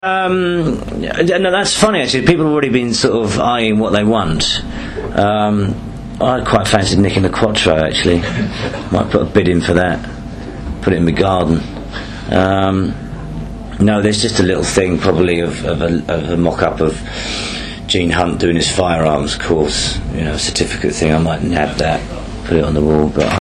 So here are a few I’ve edited from my interview with Phil and saved in MP3 format.
As you may have read in the feature, our final series conversation took place in his dressing room on set in Bermondsey just days before filming ended in February.